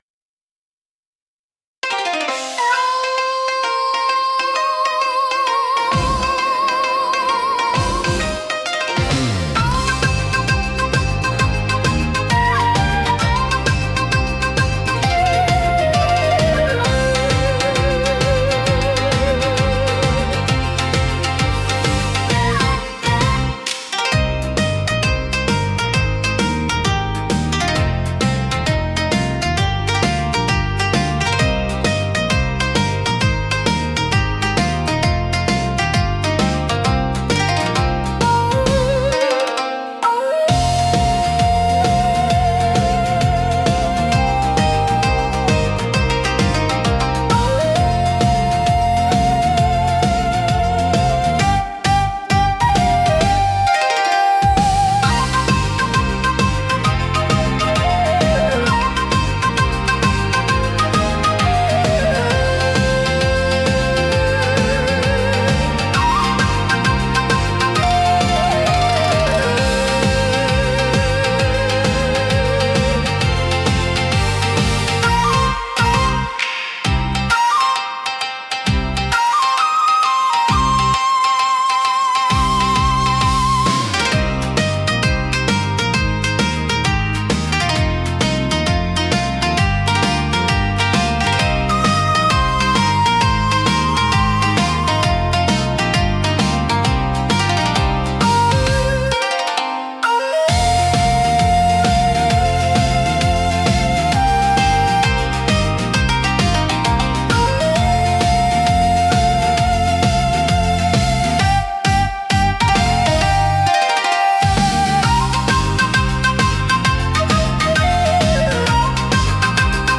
聴く人に日本独特の風情と心の安らぎを届けるジャンルです。